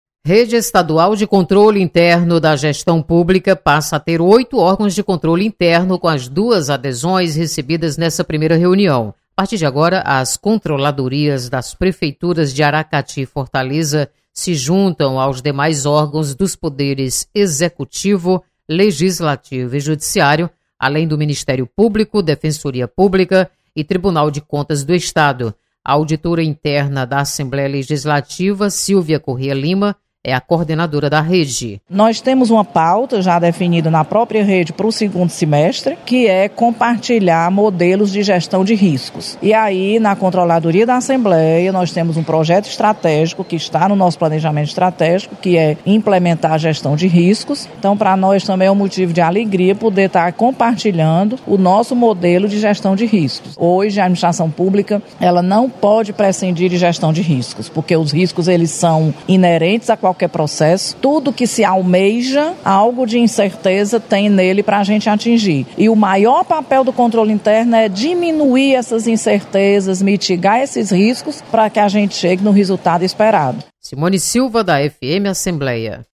Ampliada rede de controle interno. Repórter